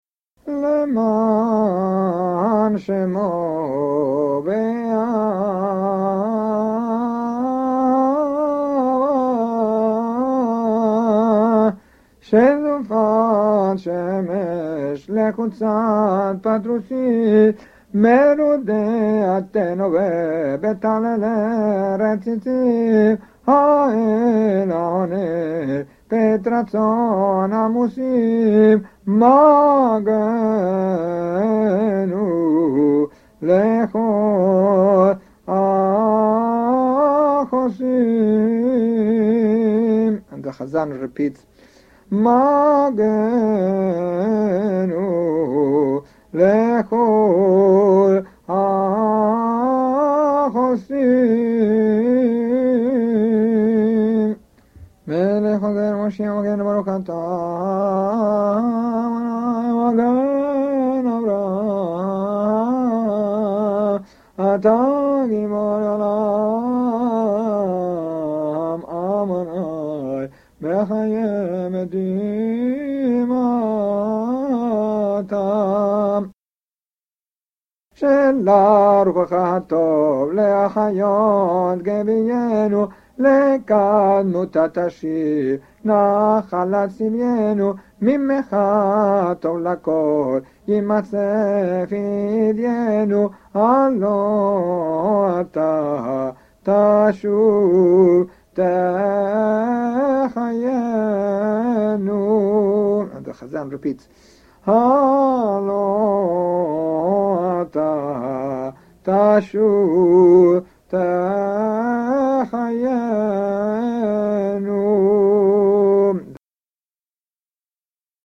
The Amidah begins with a special recitative tune (used only for the Prayers for Dew and Rain), and includes the 4 hymns.